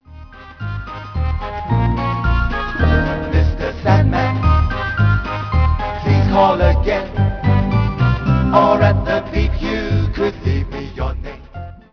This song has a music background and 4 voices (3 parts).